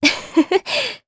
Aselica-Vox_Happy1_jp.wav